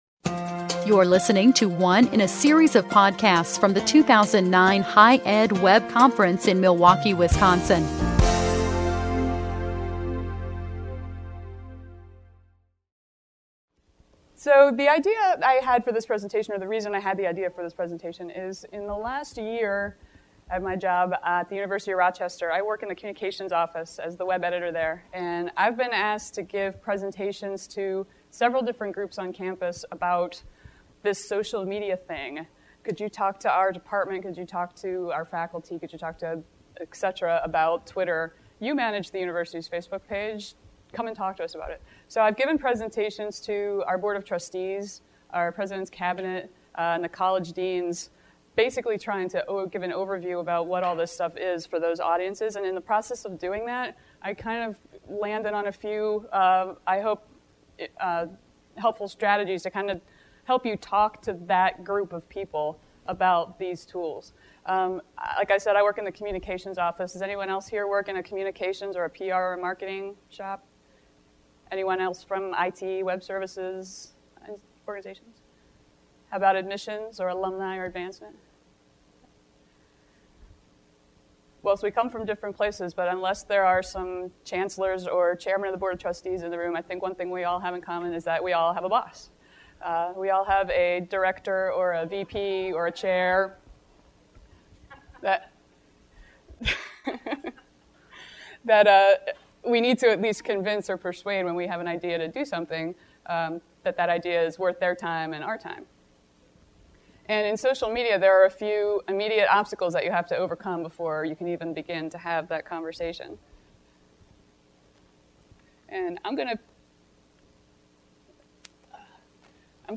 SOC3 Social Media Track Wright B Monday, 10:45 – 11:30 AM ... and Facebook and YouTube, etc. Perhaps you've dabbled in social media personally and have some ideas on how your institution might participate.